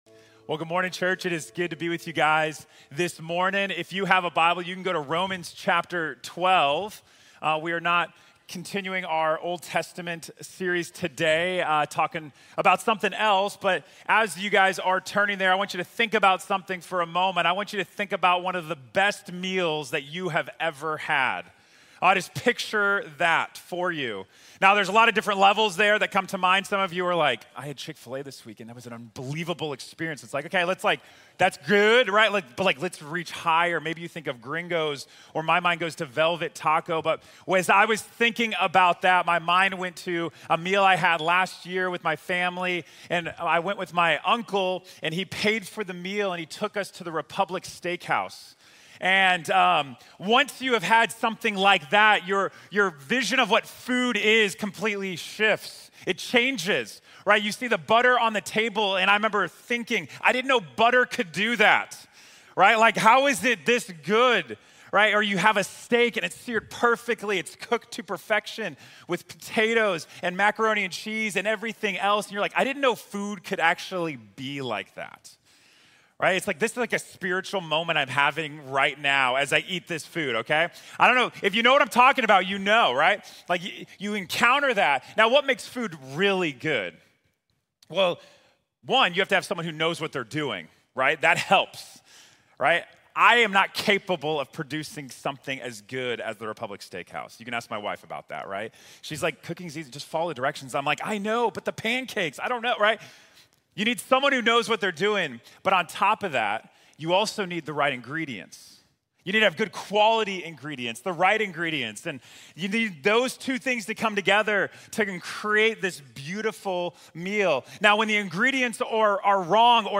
Conexión Profunda | Sermón | Grace Bible Church